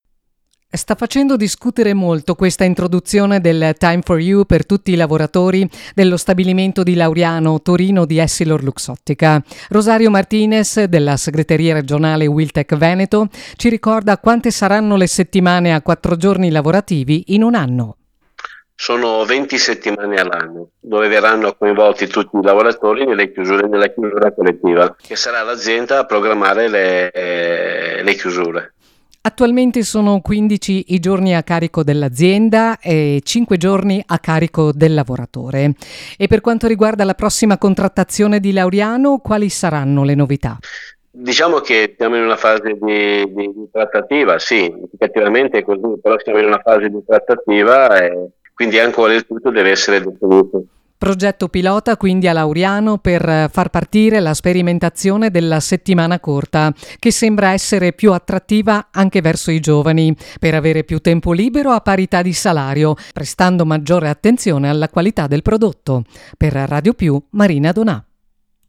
dal giornale radio principale del 19 settembre 2025